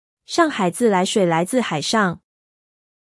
• ピンイン: shàng hǎi zì lái shuǐ lái zì hǎi shàng
「上海」と「海上」など、似た音節が続くため、各単語のアクセントに注意して発音することが大切です。